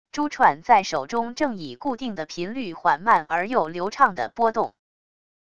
珠串在手中正以固定的频率缓慢而又流畅地拨动wav音频